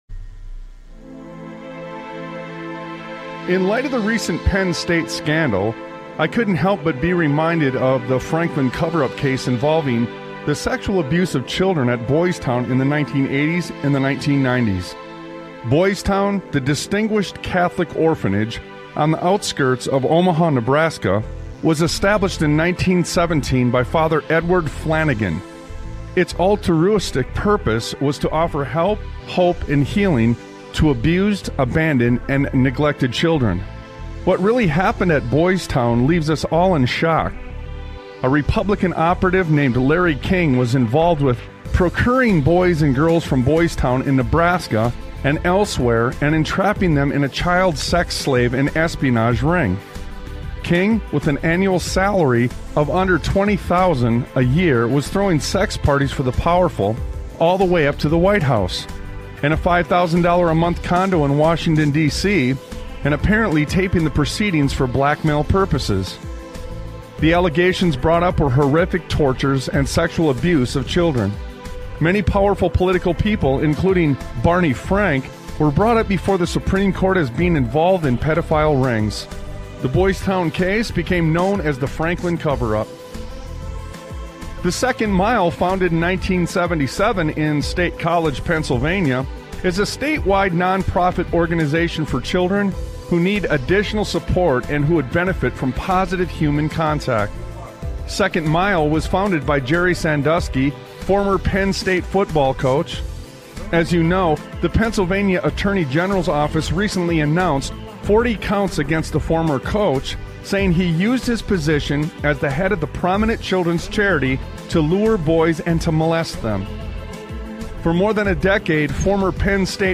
Talk Show Episode, Audio Podcast, Sons of Liberty Radio and Have You Taken A Look As To What Is On The Walls? on , show guests , about Have You Taken A Look As To What Is On The Walls, categorized as Education,History,Military,News,Politics & Government,Religion,Christianity,Society and Culture,Theory & Conspiracy